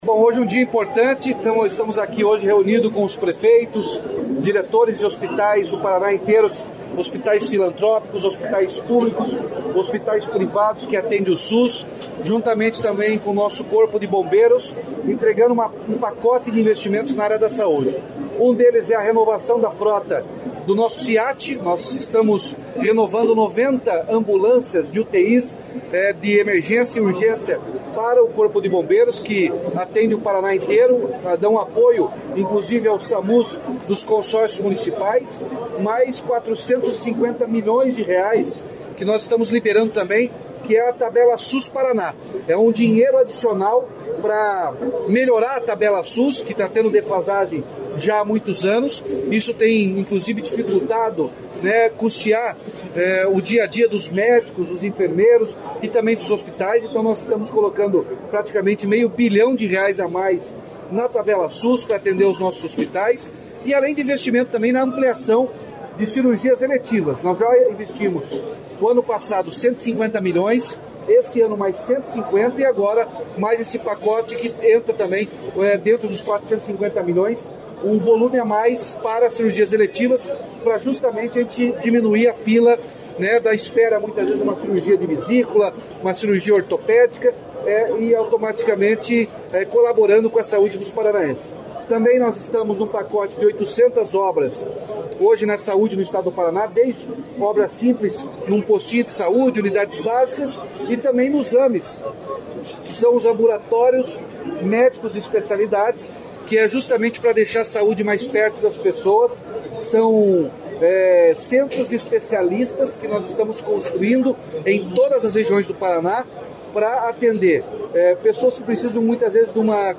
Sonora do governador Ratinho Junior sobre os R$ 403 milhões de aporte adicional anunciados para a Saúde do Paraná